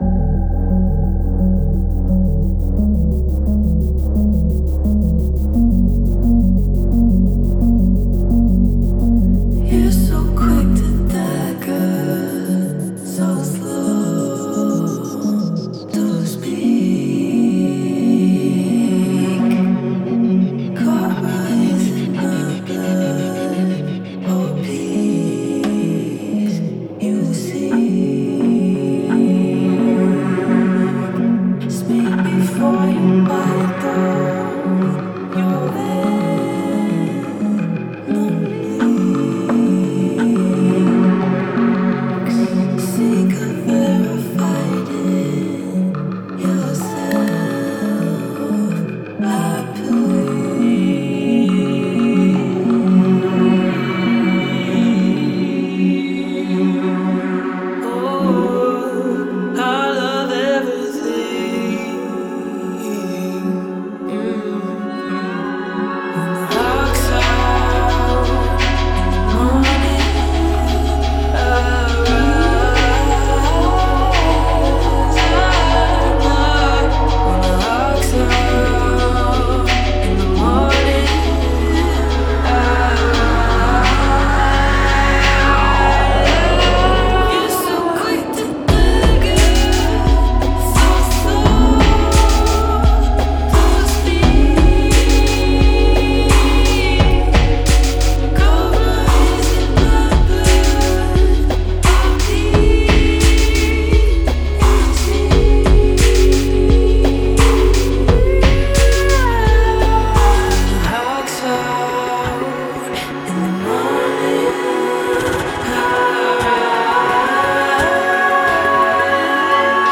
Dark Moog arpeggios